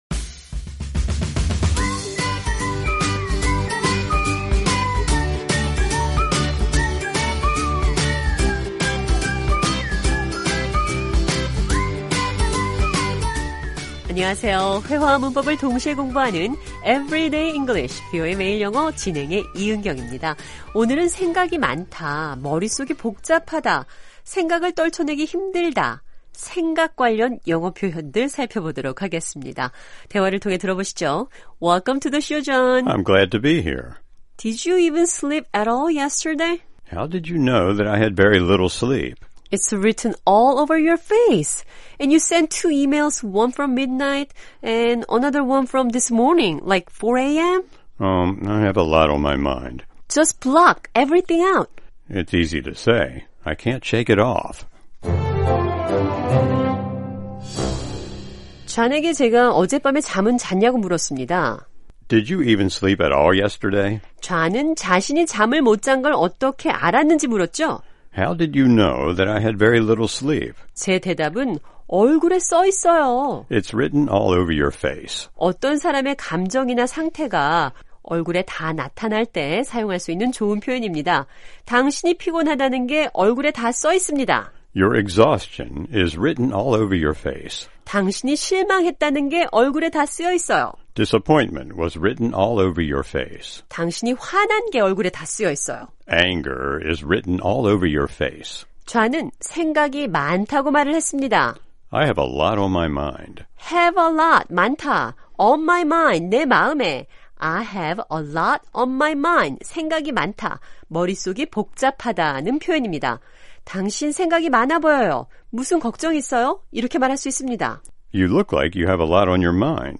오늘은 생각이 많다, 머리속이 복잡하다, 생각을 떨쳐내기 힘들다, 생각 관련 영어 표현들 살펴보겠습니다. 대화를 통해 들어보시죠.